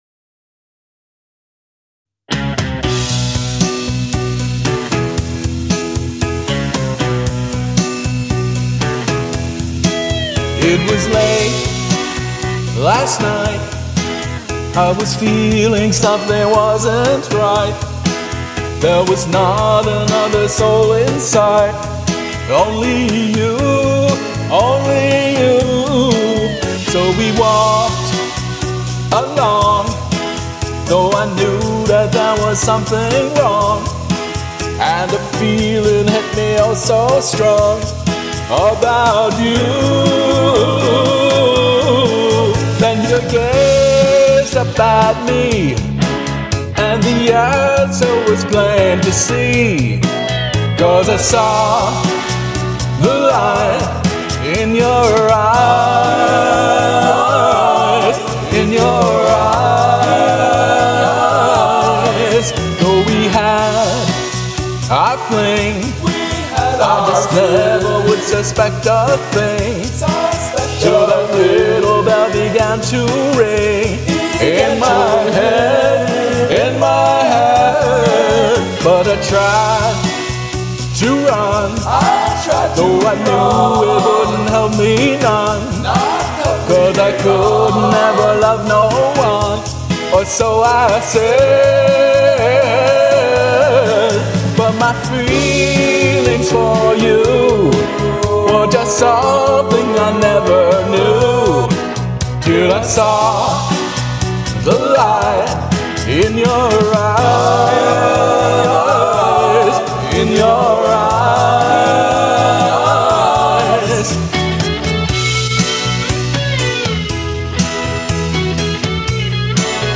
vocals
karaoke arrangement